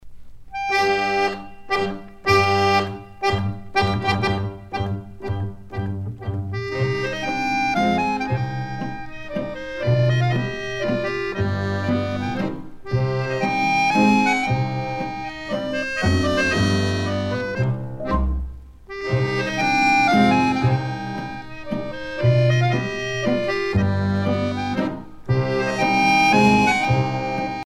danse : valse lente ; danse : kujawiak (Pologne)
Pièce musicale éditée